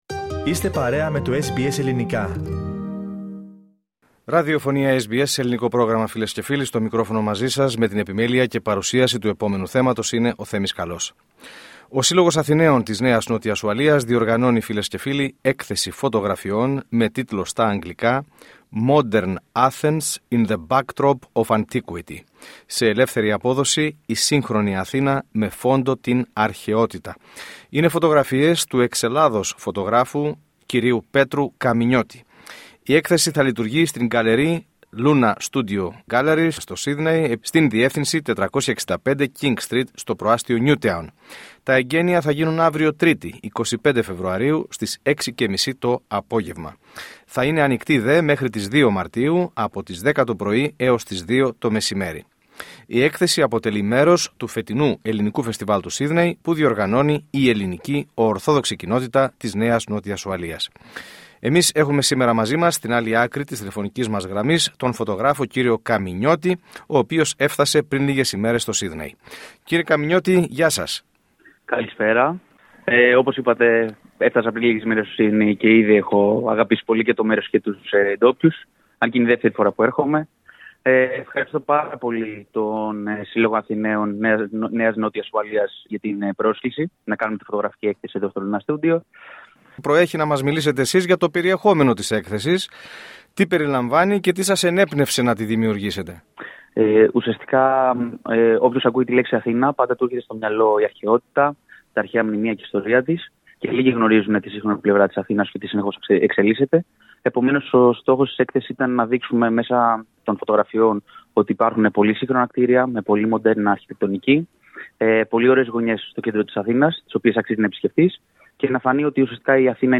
Σε συνέντευξή του προς το πρόγραμμά μας